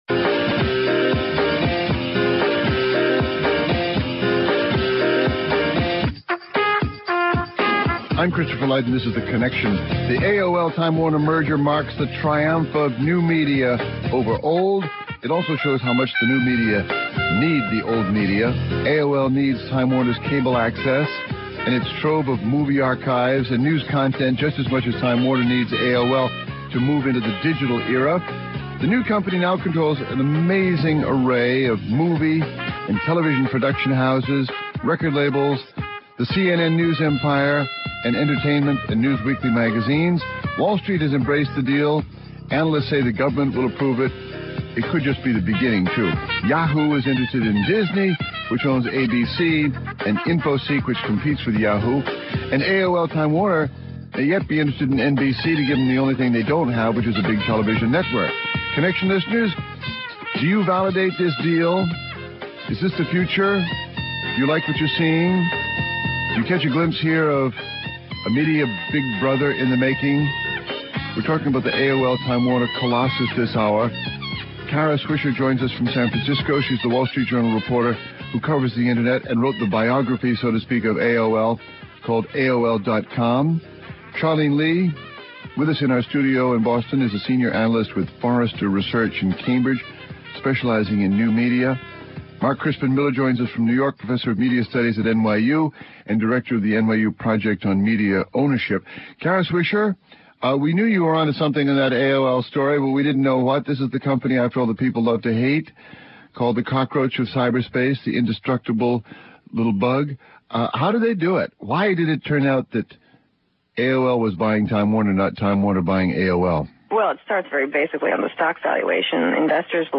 (Hosted by Christopher Lydon) Guests